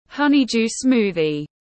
Sinh tố dưa lê tiếng anh gọi là honeydew smoothie, phiên âm tiếng anh đọc là /ˈhʌnɪdju ˈsmuː.ði/
Honeydew smoothie /ˈhʌnɪdju ˈsmuː.ði/